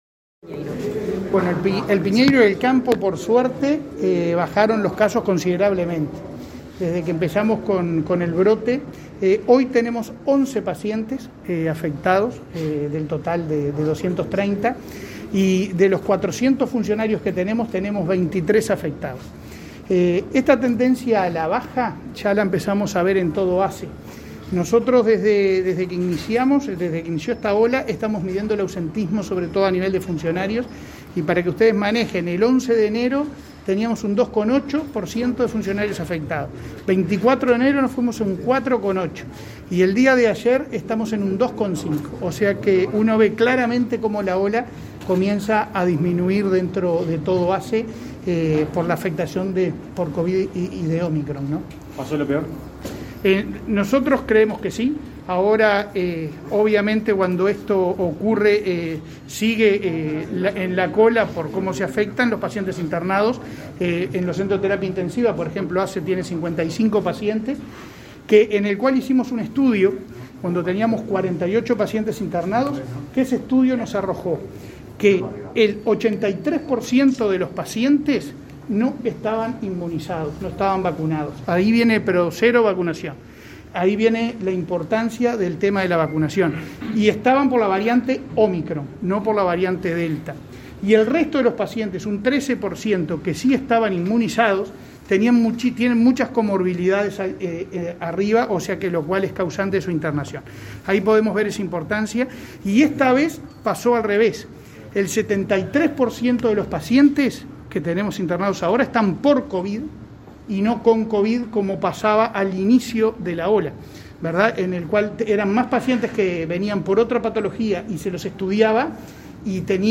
Declaraciones del presidente de ASSE a la prensa
El presidente de ASSE, Leonardo Cipriani, participó este martes 15 en la inauguración de obras en la fundación Pérez Scremini y, luego, dialogó con la